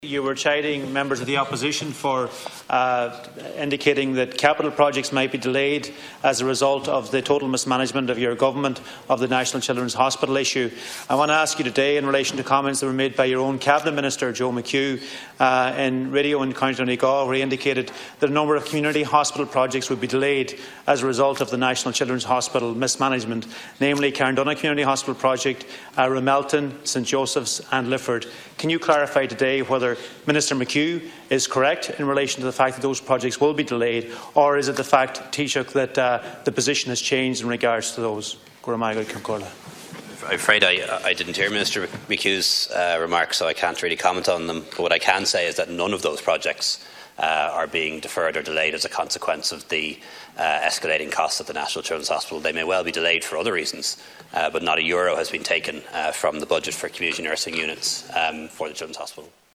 Donegal Deputy Charlie McConalogue challenged Leo Varadkar on Minister McHugh’s comments in the Dail to which the Taoiseach stated that other reasons may be behind the delay in works being carried out: